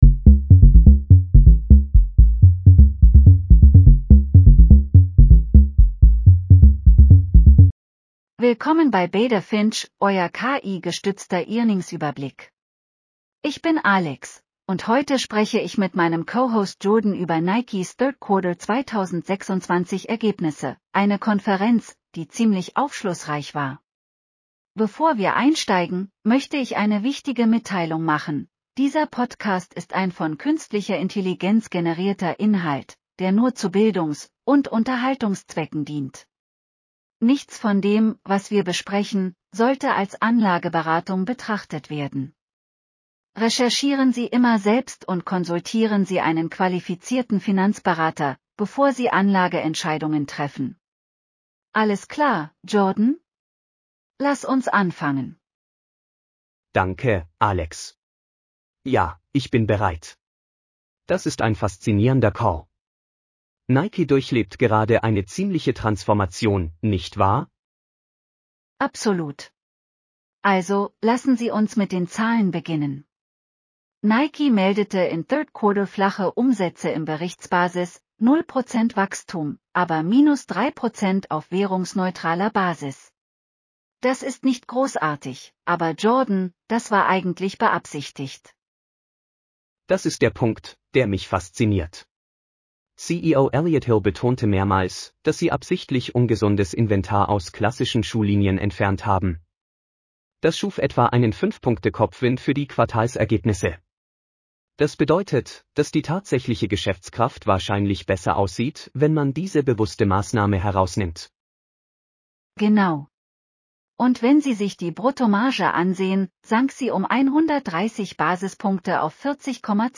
Nike Q3 2026 earnings call breakdown.